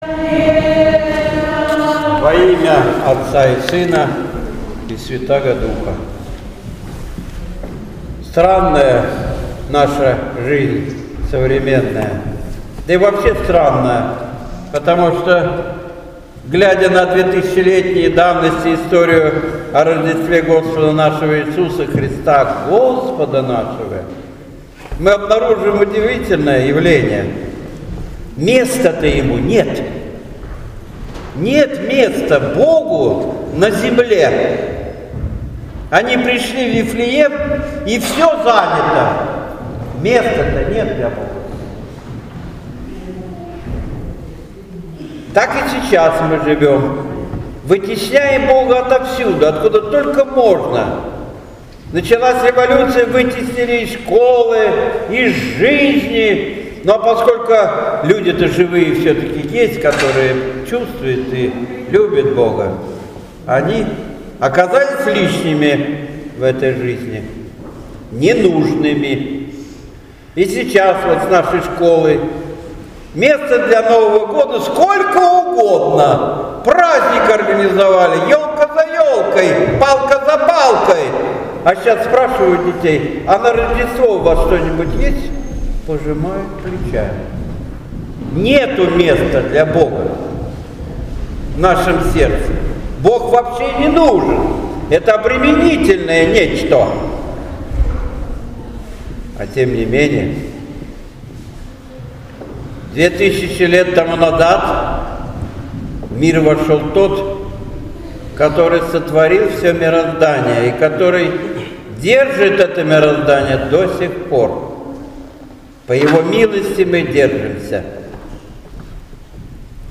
8 января 2017 г. Борисово. Приход церкви во имя Покрова Божией Матери
Божественная Литургия 8 января 2017 года.Собор Пресвятой Богородицы